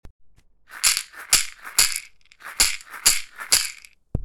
ひょうたん底 バスケットマラカス　アフリカ 民族楽器 （n121-16）
ブルキナファソで作られたバスケット素材の素朴なマラカスです。
水草とひょうたんと木の実でできています。
やさしいナチュラルな乾いた音を出します。
この楽器のサンプル音